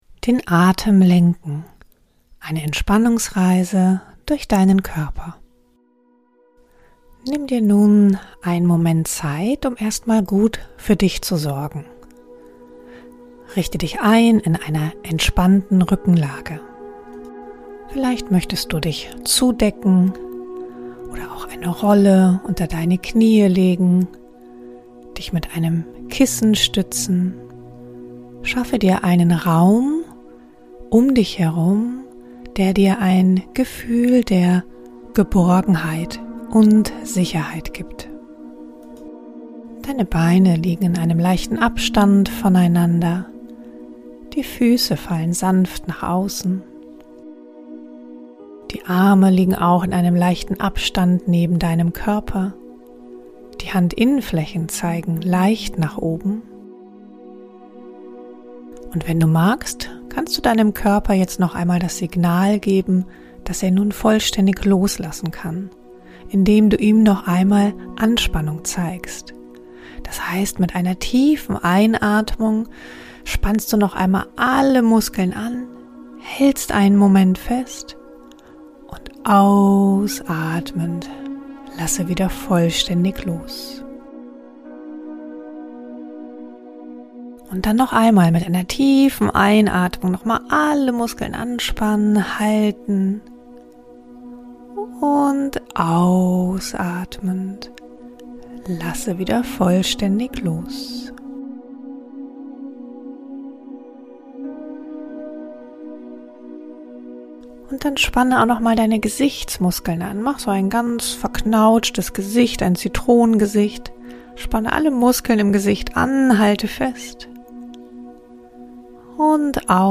In dieser besonderen Podcastfolge lade ich dich ein, eine Entspannungsreise zu erleben, die ich auch in jedem meiner Livekurse am Ende anleite.